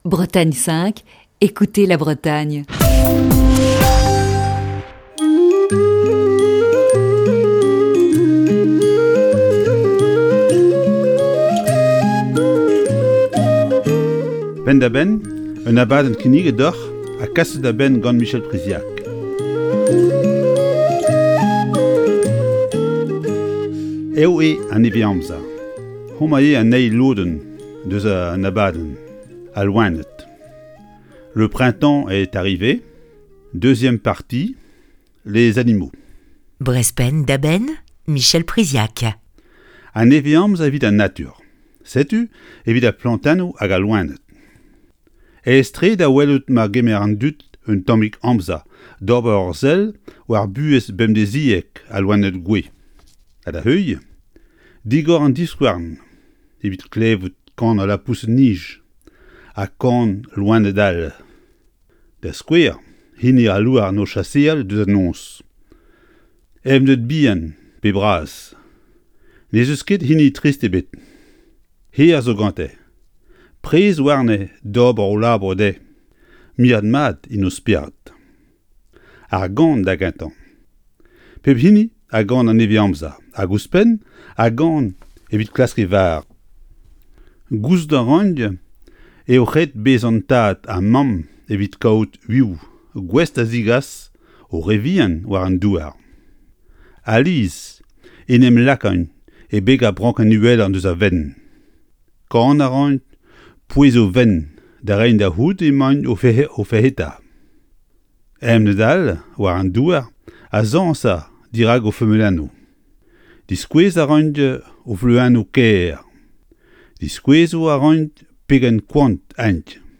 Chronique du 9 mars 2021.